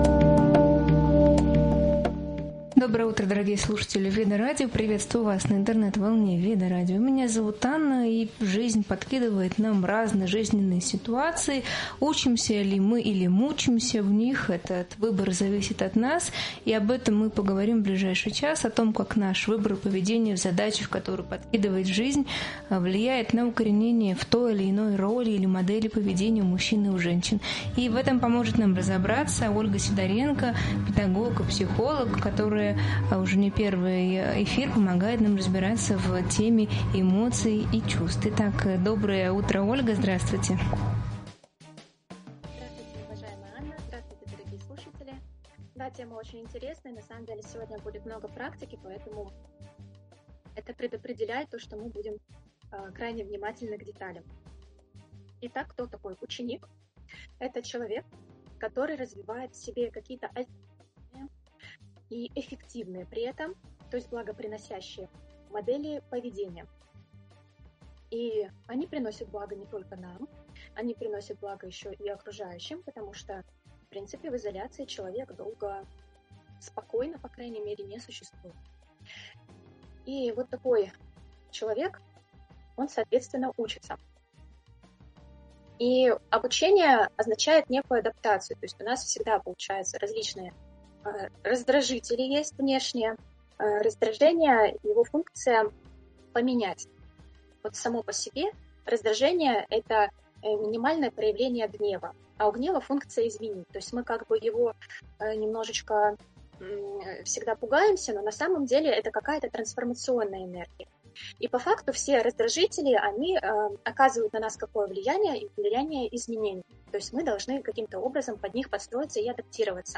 В этом эфире обсуждаются тонкости отношений, эмоциональная нагрузка и баланс в семье. Разбираем, как привязанность, коммуникация и уважение к границам влияют на совместную жизнь, роль родителей и детей, распределение обязанностей и гиперопеку. Спикеры делятся практическими методами управления эмоциями, улучшения взаимодействия с партнёром и сохранения внутреннего спокойствия, а также важностью понимания собственных потребностей.